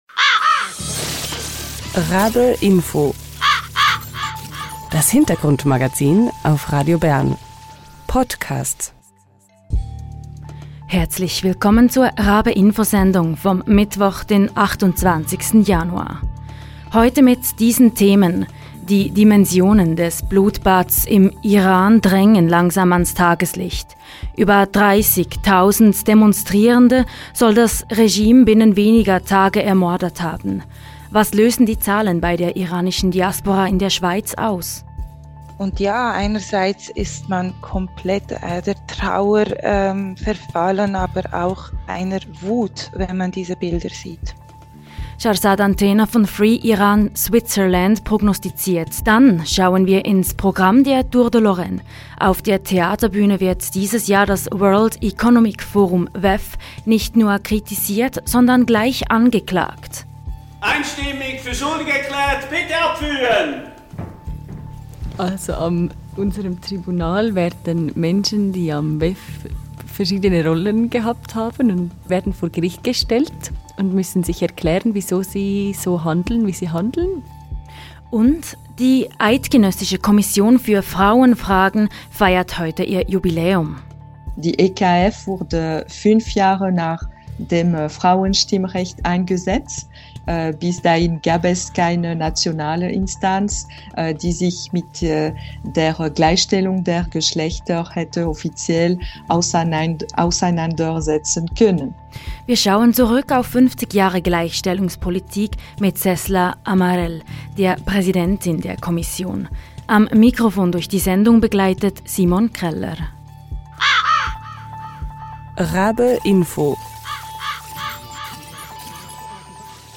Interview. Dann schauen wir ins Programm der Tour de Lorraine: Auf der Theaterbühne wird dieses Jahr das World Economic Forum WEF nicht nur kritisiert, sondern gleich angeklagt: UND: Die Eidgenössische Kommission für Frauenfragen feiert heute ihr Jubiläum: Wir schauen zurück auf 50 Jahre Gleichstellungspolitik, mit Cesla Amarelle, der Präsidentin der Kommission.